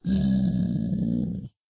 minecraft / sounds / mob / zombiepig / zpig2.ogg